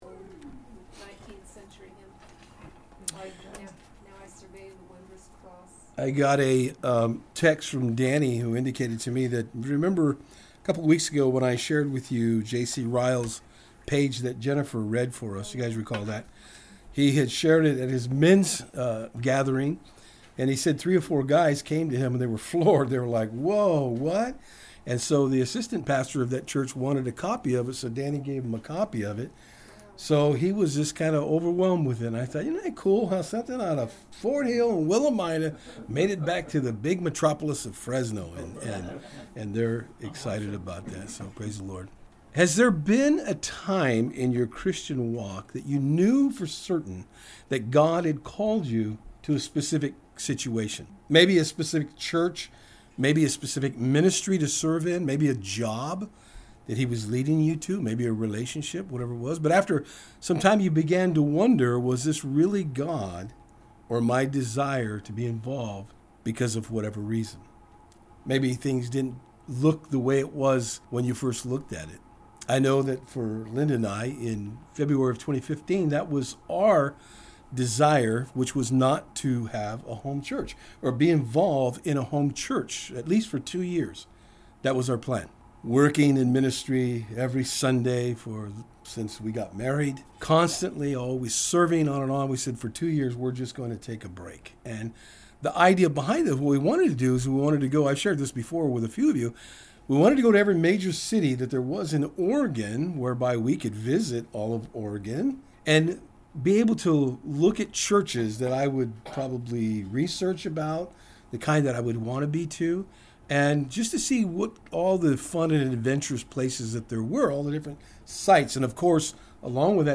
NOTICE: ( From here on out the background noise in our recordings will be the pellet stove providing heat)